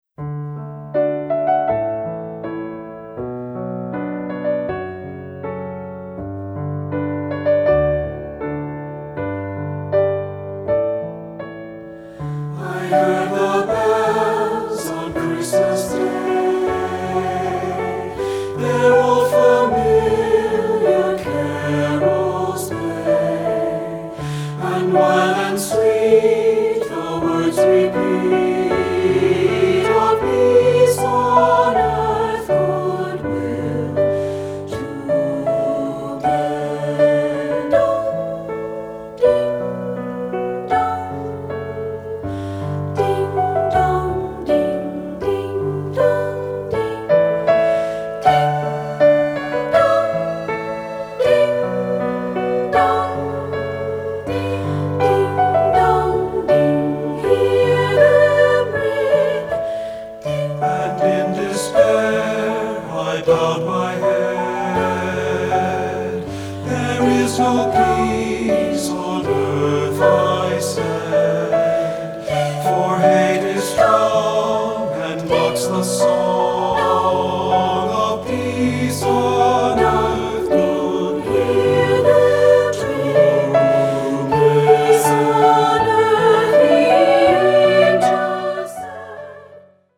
Choral Christmas/Hanukkah
SAB